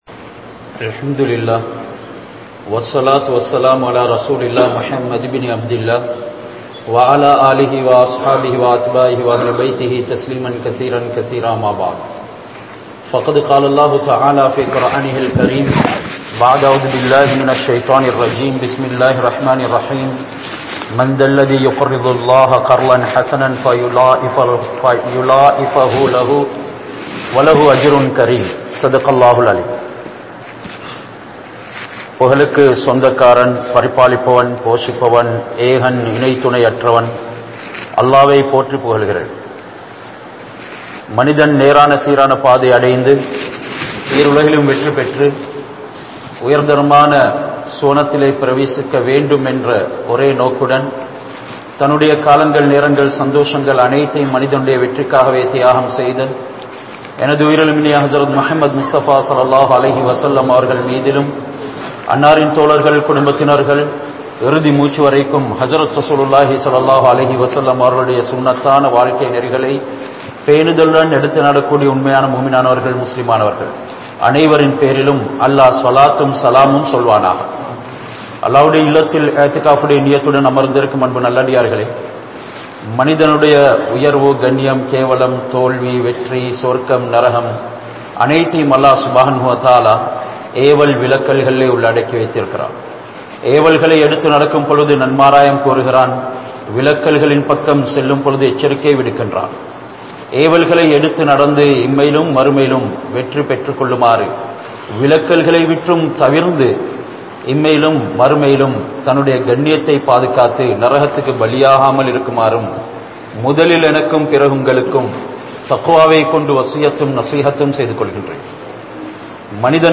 Kalaa Kathrai Maattrum Sathaqa (கழா கத்ரை மாற்றும் ஸதகா) | Audio Bayans | All Ceylon Muslim Youth Community | Addalaichenai
Kalugamuwa Jumua Masjidh